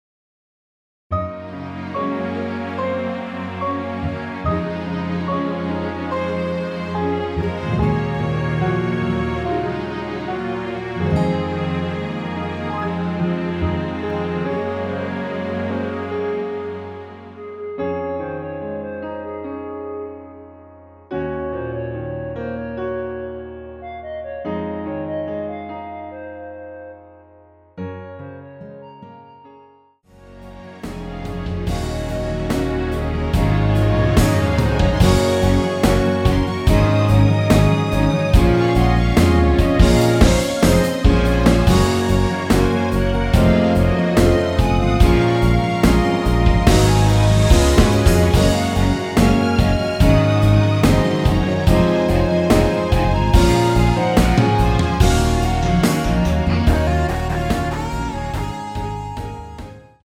원키에서(-1)내린 멜로디 포함된 MR 입니다.
Db
앞부분30초, 뒷부분30초씩 편집해서 올려 드리고 있습니다.
중간에 음이 끈어지고 다시 나오는 이유는